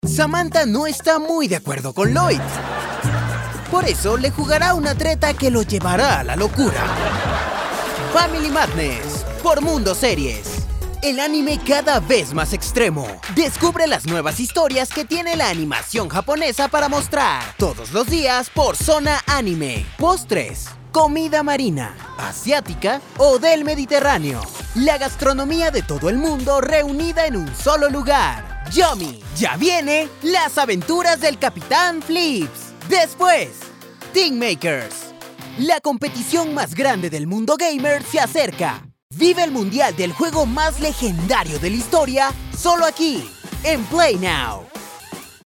VOICE OVER TALENT
Bumpers TV